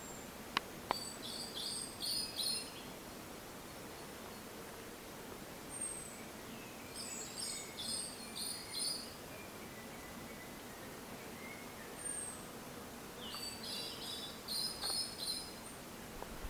Blacksmith Thrush (Turdus subalaris)
Life Stage: Adult
Location or protected area: Parque Provincial Cruce Caballero
Condition: Wild
Certainty: Observed, Recorded vocal